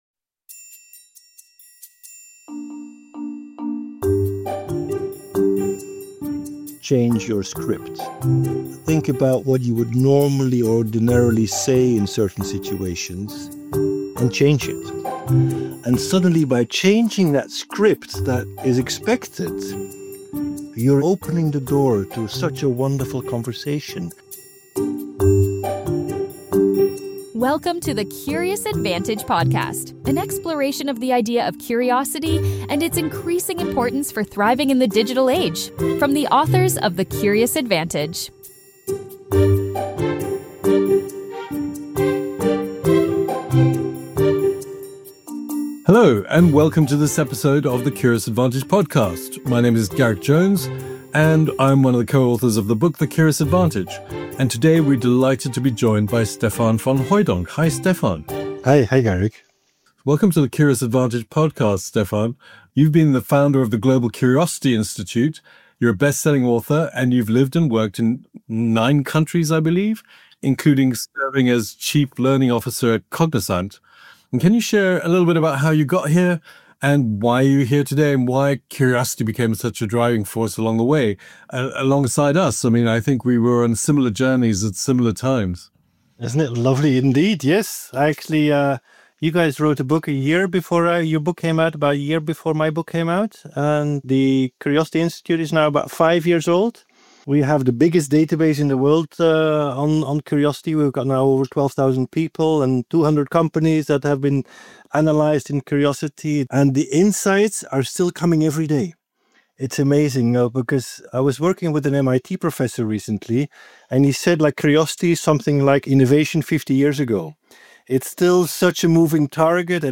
From permission, awareness, and intentionality in building a culture of curiosity, to surprising data insights, the role of language, and the impact of environment — this conversation unpacks the forces that spark or stifle curiosity. They also discuss the challenges of conformity, the many dimensions of curiosity, and what the rise of AI means for our curious minds.